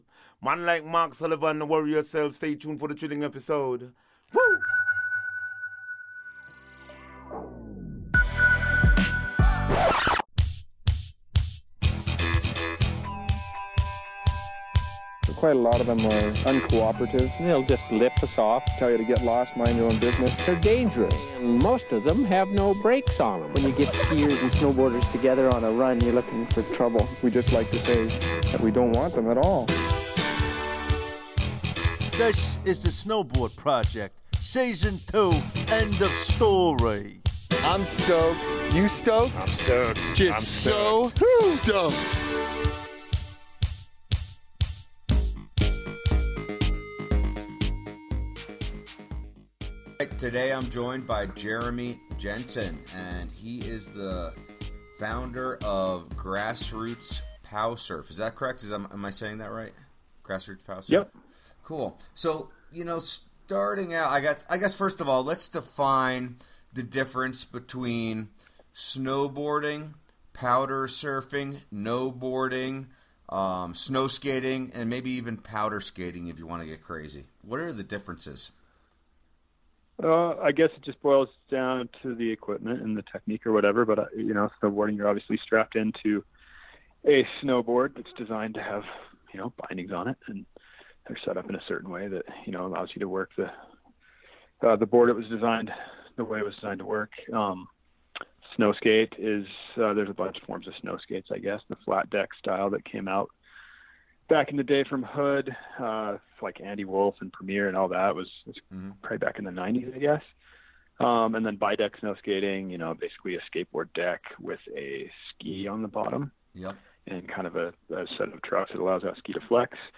Interview - The Snowboard Project Podcast